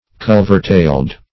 Search Result for " culvertailed" : The Collaborative International Dictionary of English v.0.48: Culvertailed \Cul"ver*tailed`\ (-t?ld`), a. United or fastened by a dovetailed joint.